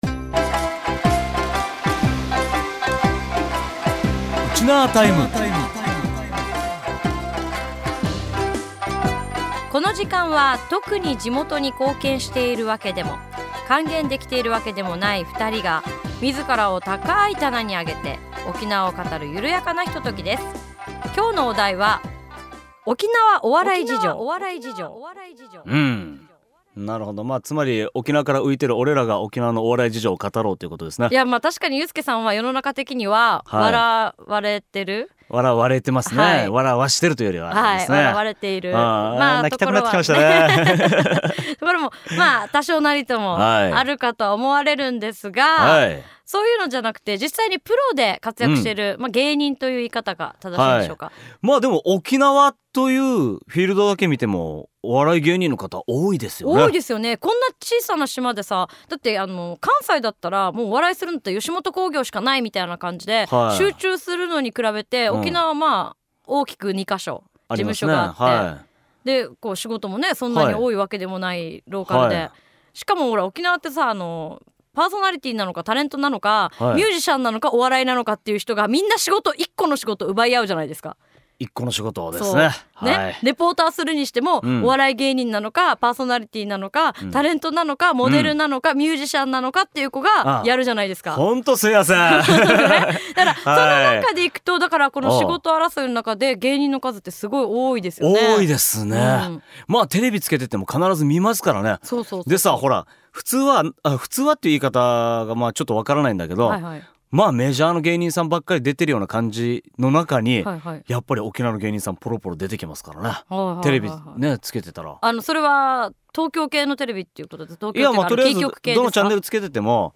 その理由を二人が検証します。